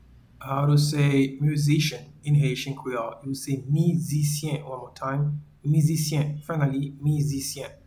Pronunciation:
Musician-in-Haitian-Creole-Mizisyen.mp3